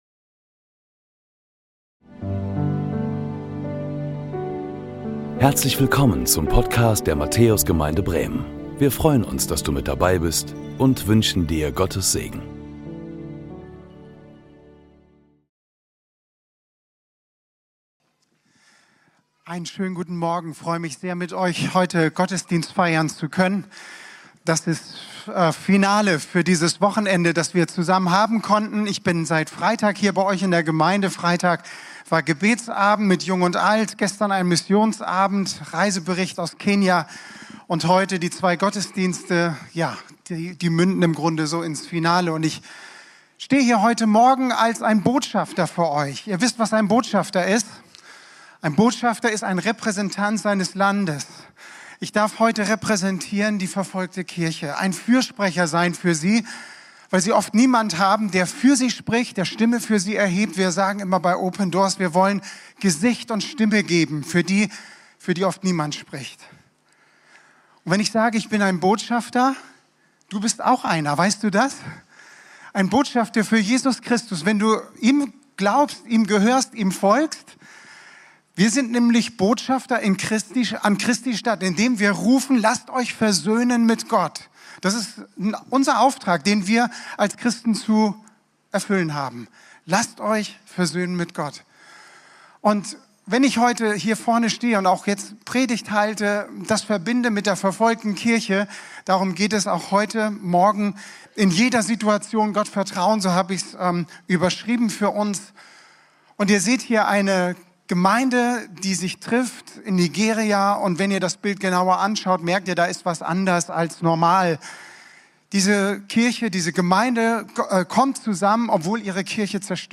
Predigten der Matthäus Gemeinde Bremen